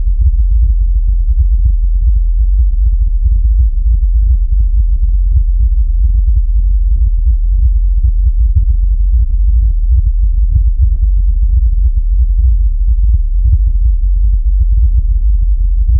صوت اهتزاز النجوم.
Star-Vibration.mp3